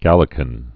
(gălĭ-kən)